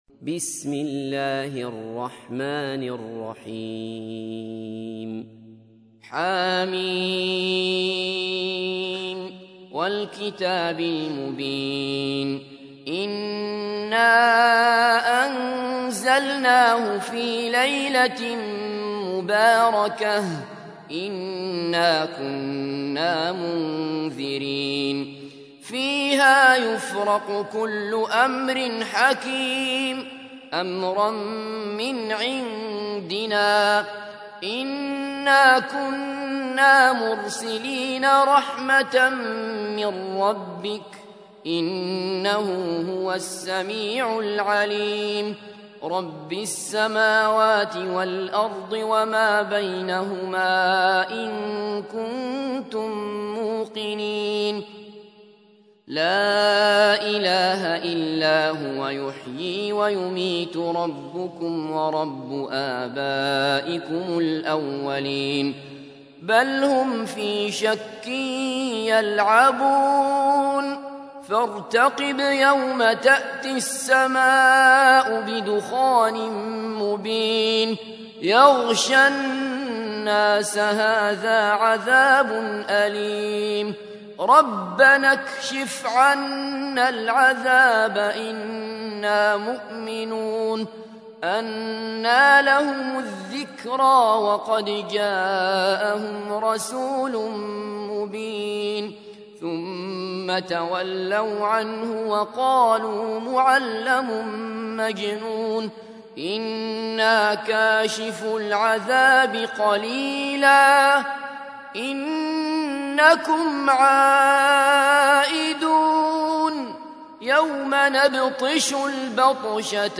تحميل : 44. سورة الدخان / القارئ عبد الله بصفر / القرآن الكريم / موقع يا حسين